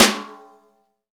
SNR XEXTS0JR.wav